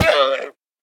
Minecraft Version Minecraft Version snapshot Latest Release | Latest Snapshot snapshot / assets / minecraft / sounds / mob / llama / death2.ogg Compare With Compare With Latest Release | Latest Snapshot